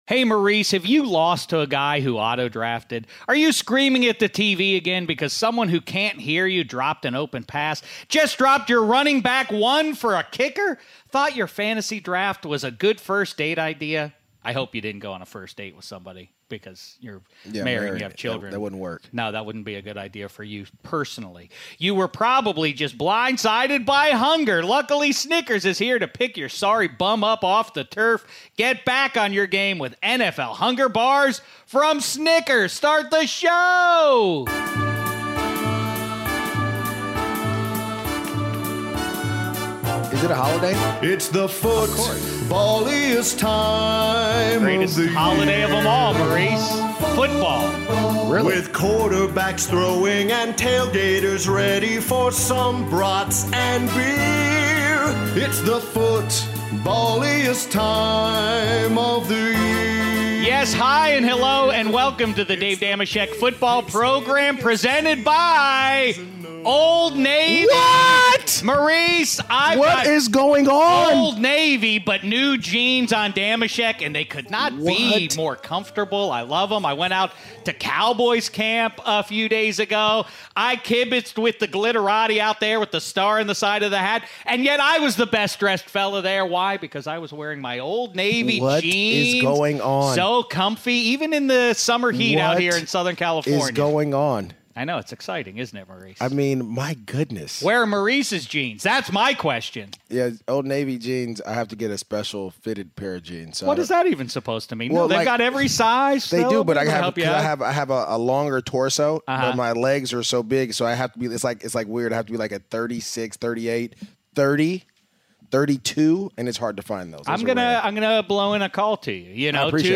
Dave Dameshek is joined in Studio 66 by our old pal Maurice Jones-Drew to go over all the news leading into Week 1.
Finally, Shek meets up with David Carr in an elevator to ask him about his brother's thoughts on the Khalil Mack trade, if teams should start rookie QBs from day one and win, place, show for the 2018 rookie QB class (41:35).